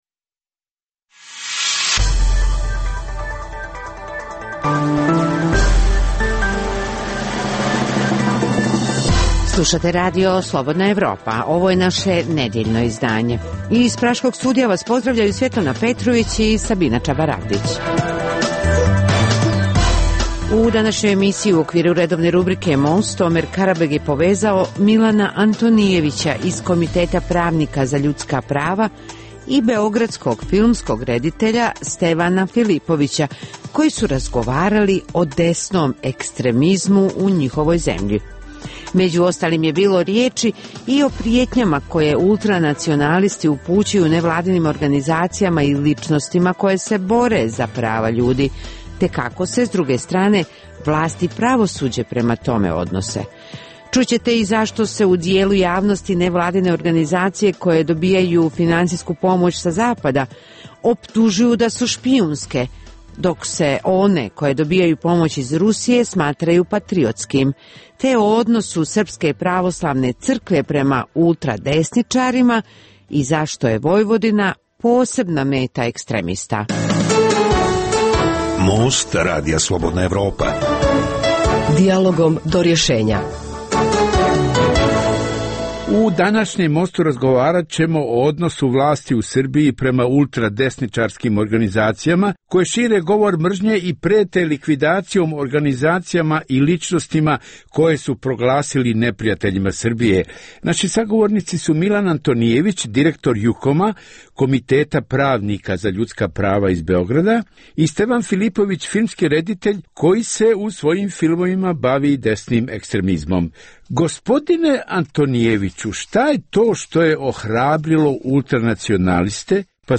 u kojem ugledni sagovornici iz regiona razmtraju aktuelne teme. Drugi dio emisije čini program "Pred licem pravde" o suđenjima za ratne zločine na prostoru bivše Jugoslavije.